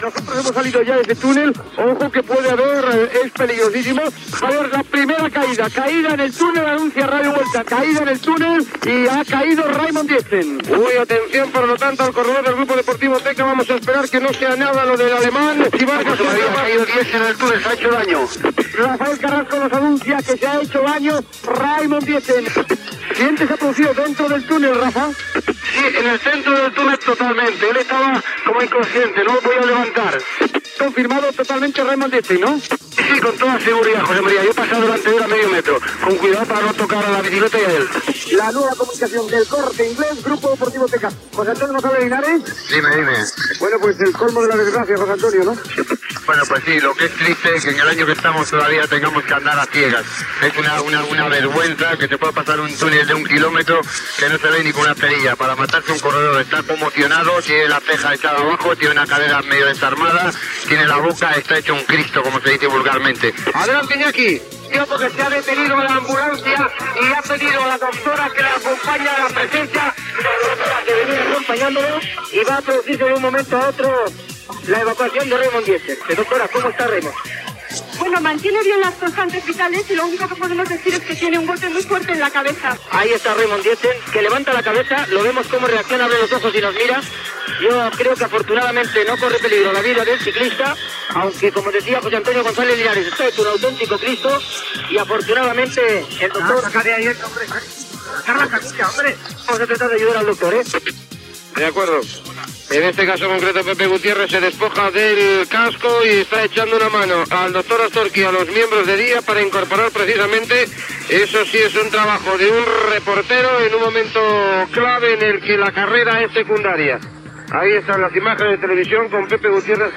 Transmissió de la Vuelta ciclista a España de l'any 1989. Narració de la caiguda del ciclista Reimund Dietzen, que es va retirar del ciclisme professional a causa de les lessions patides dins el tunel de Cotefablo (Huesca) sense il·luminació.
Esportiu